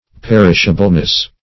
Perishableness \Per"ish*a*ble*ness\, n.